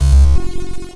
retro_fail_sound_03.wav